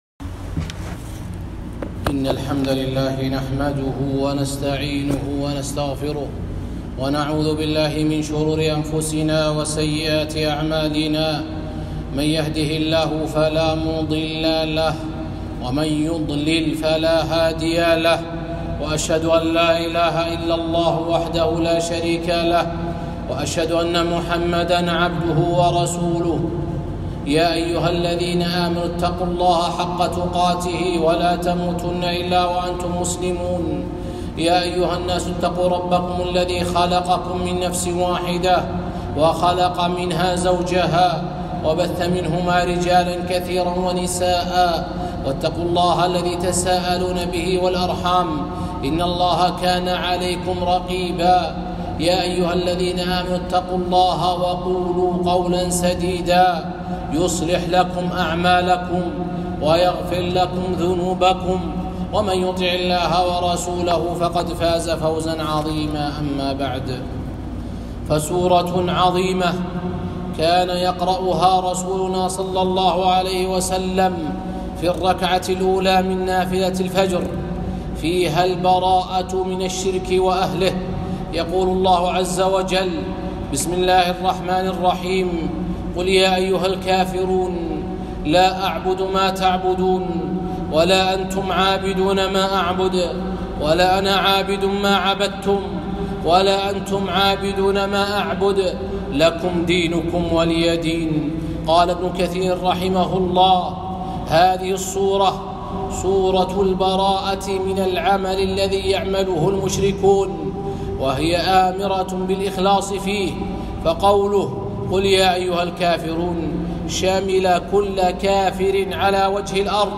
خطبة - سورة كان يقرأ بها رسولنا ﷺ في نافلة الفجر